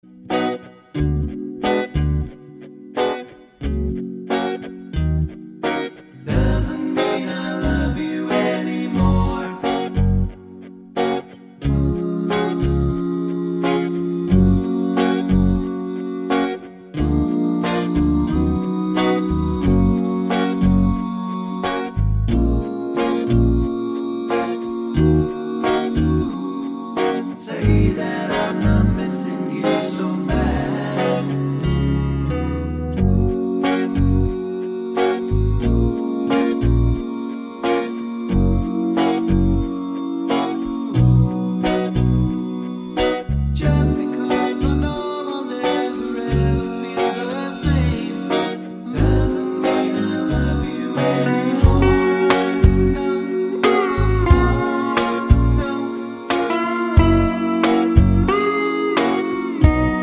no Backing Vocals Jazz / Swing 3:03 Buy £1.50